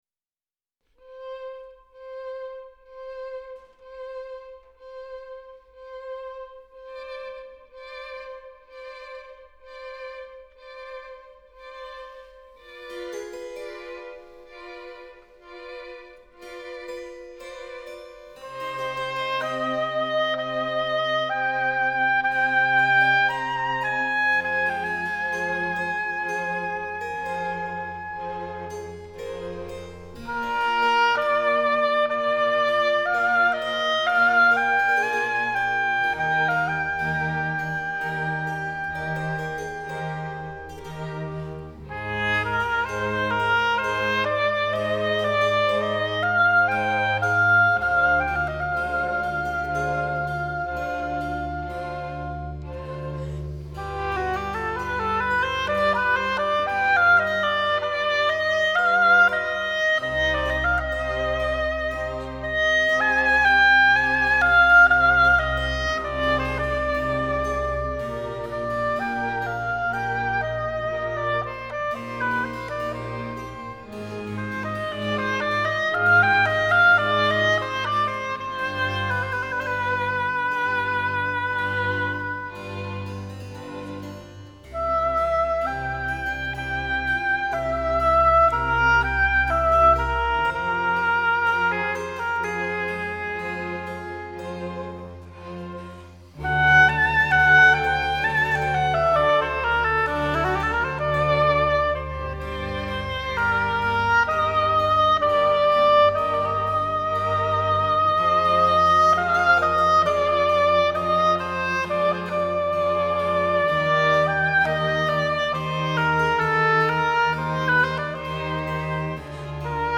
Our chamber ensemble is formed by talented Prague Symphony Orchestra memebers. The goal is to bring the best chamber music from Barque to contemporary to our audience.
Arcangelo Corelli - Concerto for oboe and strings - 5 th movement [3938 kB] Benedetto Marcello - Adagio for oboe and strings [7824 kB] Antonio Vivaldi - Concerto D Major - 3 rd movement [3482 kB]